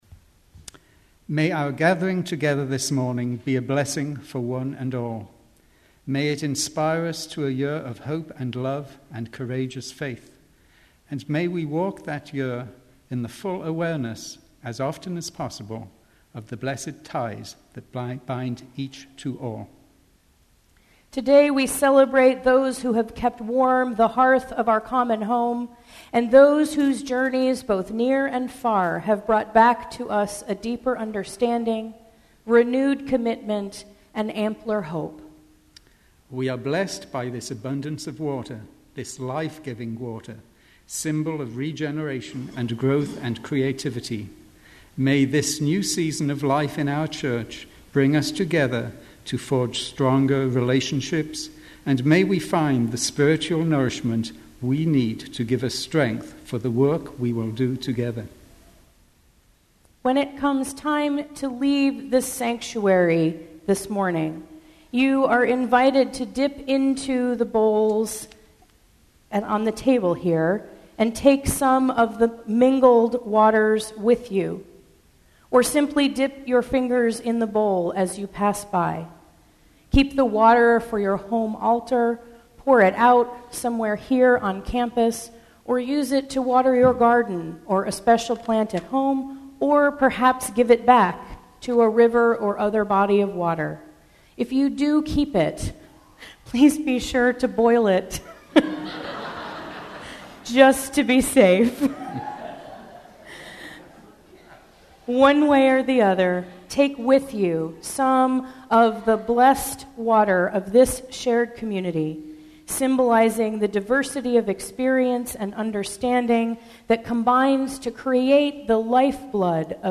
We return to the beloved custom of combining water from our summer experiences, whether close to home or far away. As we approach the beginning of the church year, join in this symbolic gathering of our community.